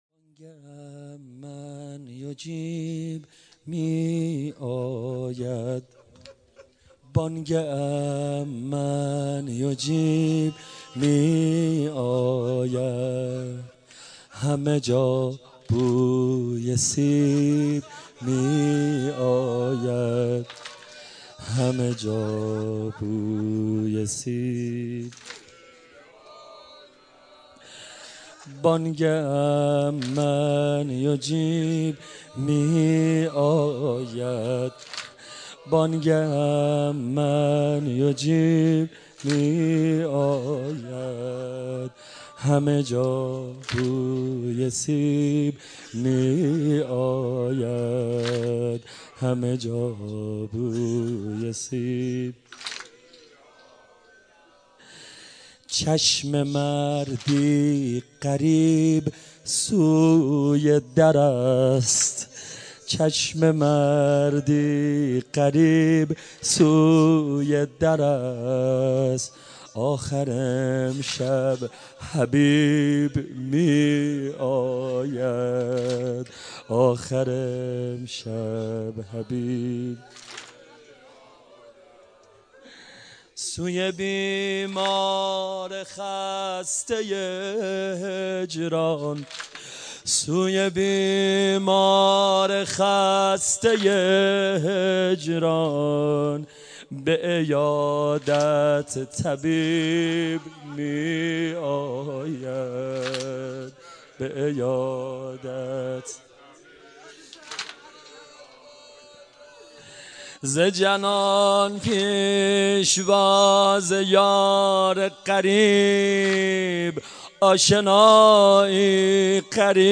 شب بیست و یکم رمضان - هیئت محبین اهل بیت علیهاالسلام
واحد | بانگ أمن یجیب می آید، همه جا بوی سیب می آید